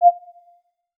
Holographic UI Sounds 42.wav